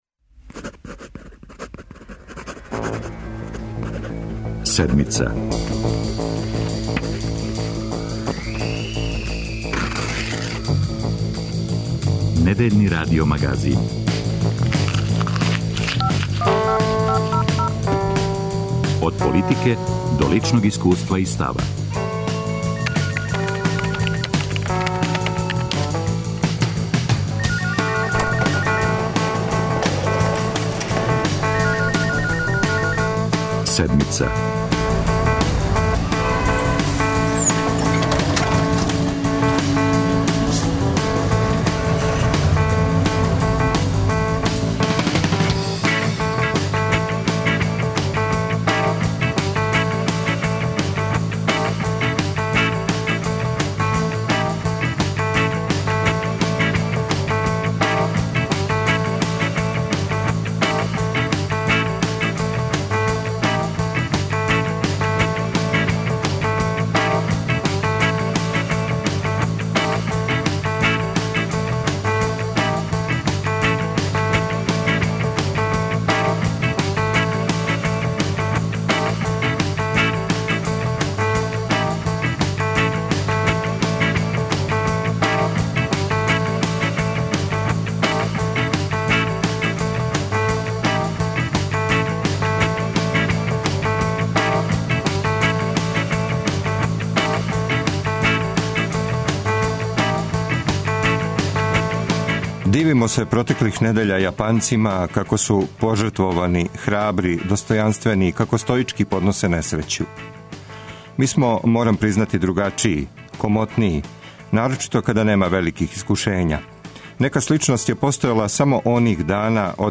Шлем, шминка и пушка - наслов је репортаже о женама професионалним војницима!